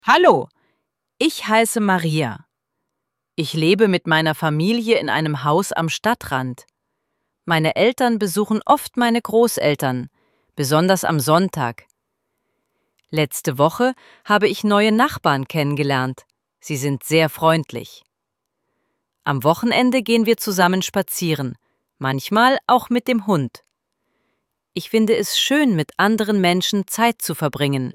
IZGOVOR – PRIČA:
ElevenLabs_Text_to_Speech_audio-63.mp3